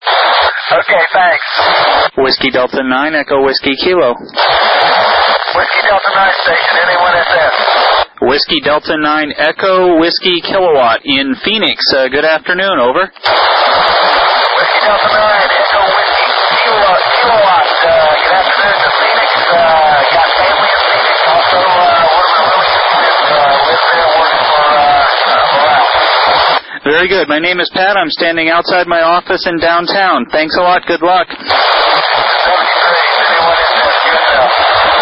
voice contact with Bill McArthur at NA1SS, using an Icom IC-T7H HT at 5W with a Maldol AH-510R 6m/2m/70cm telescoping whip.
standing in downtown Phoenix (grid DM33xl) for this contact.